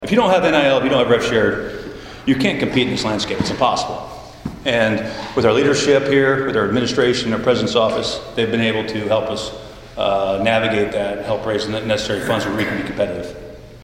talks basketball to Hopkinsville Kiwanis Club